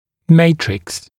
[‘meɪtrɪks][‘мэйтрикс]матрикс, матрица